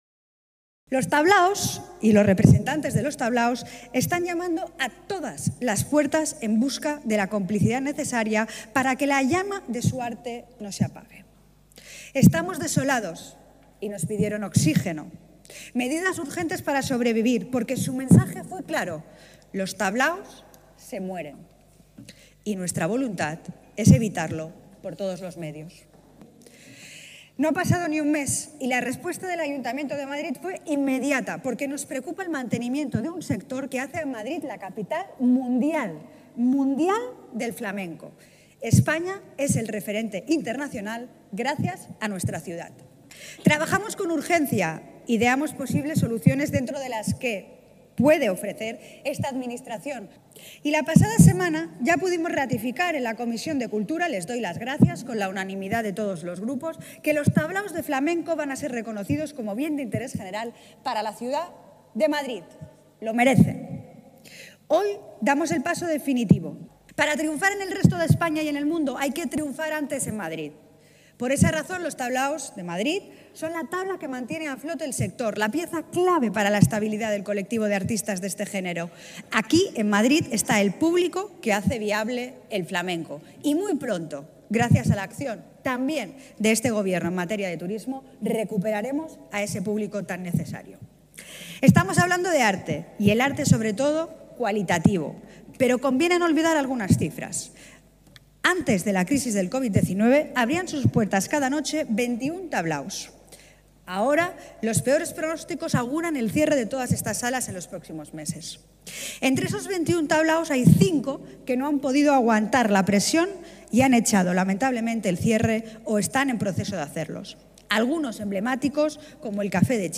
Nueva ventana:Declaraciones de Andrea Levy, delegada de Cultura, Turismo y Deporte